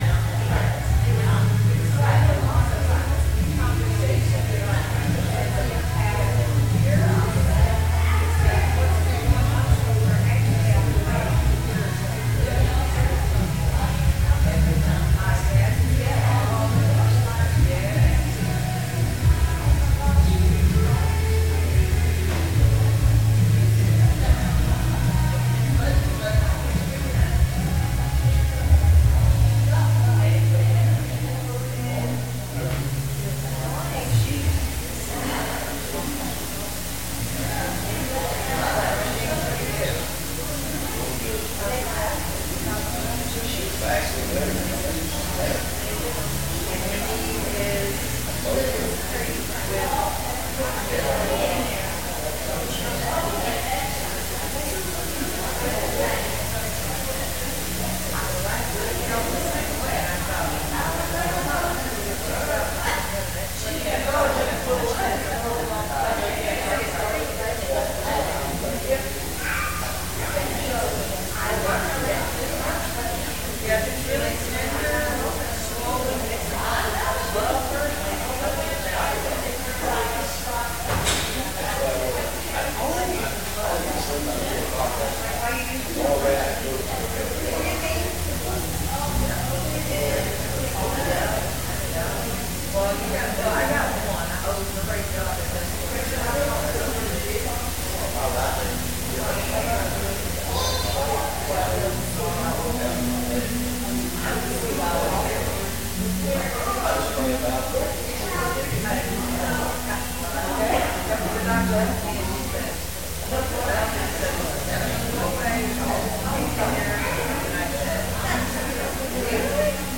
Sunday Morning Teaching